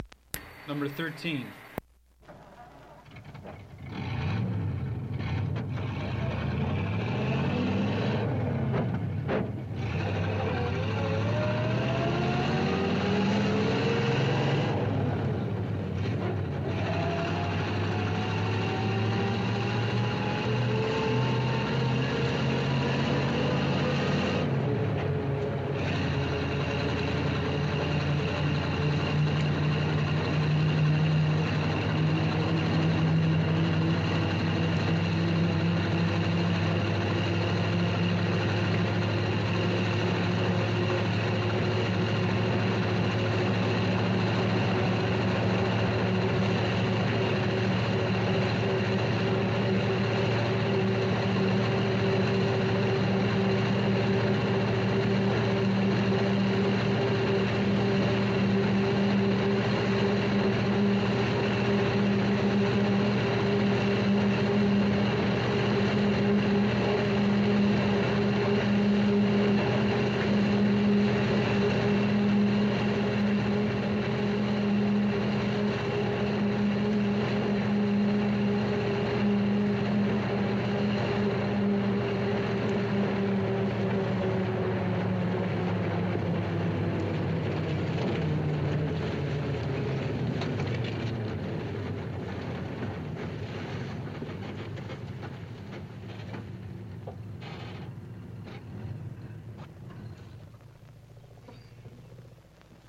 老式卡车 " G1413 老式巴士完整序列
描述：发动机启动，卡车立即加速。一些吱吱声和刘海。高音引擎，下方有低音。提升基调。最终停止了很多吱吱声。 这些是20世纪30年代和20世纪30年代原始硝酸盐光学好莱坞声音效果的高质量副本。 40年代，在20世纪70年代早期转移到全轨磁带。我已将它们数字化以便保存，但它们尚未恢复并且有一些噪音。
Tag: 卡车 交通运输 光学 经典